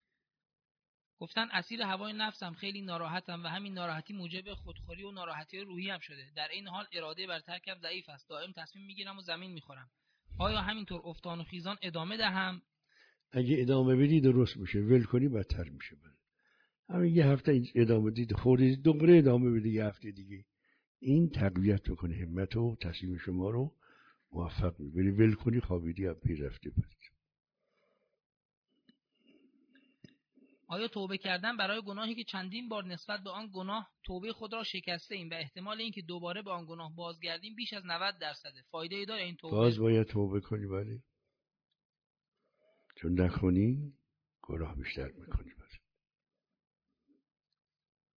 درس اخلاق